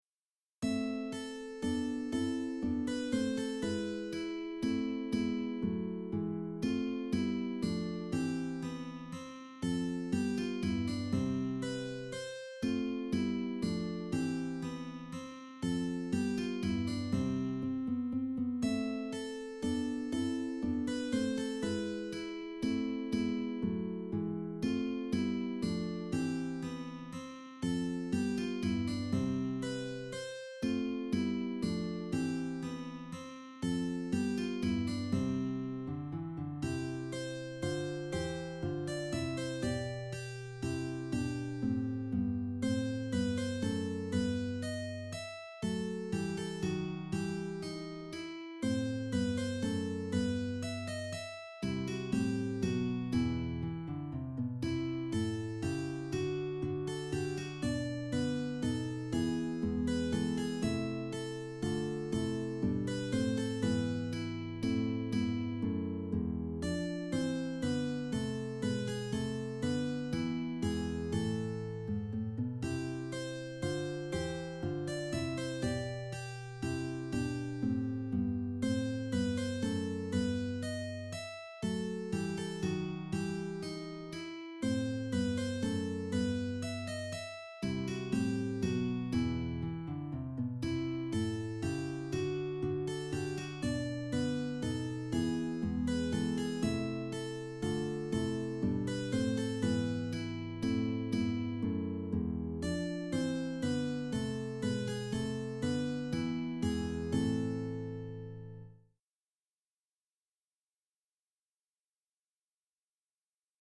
Here are nos. 1,3 & 15 arranged for mandolin and classcial guitar, along with computer-generated "performances" in mp3 format.